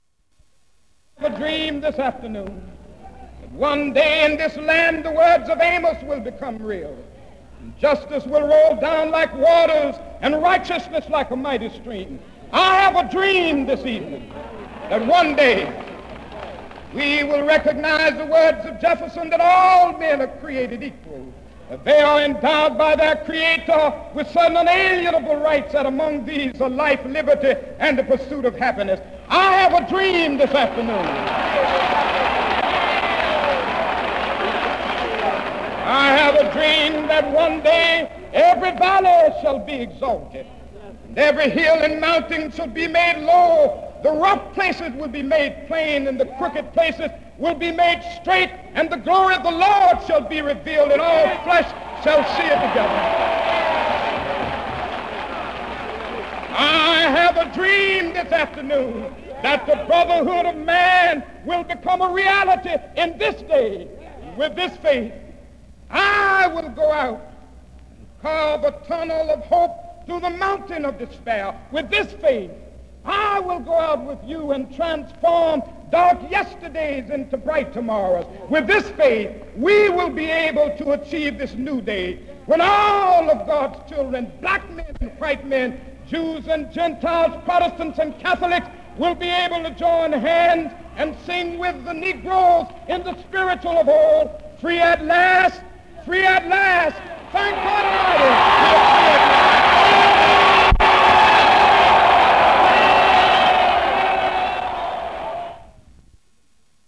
An excerpt from a speech by Martin Luther King
audio recording of this part of the speech.
The overall result, particularly when the rhythm and pronunciation of the spoken version of the speech is added into the mix, is a strong, almost incantatory, plea, full of explicit biblical allusions, for all human beings (and, given the context of the speech, American blacks in particular) to be treated equally.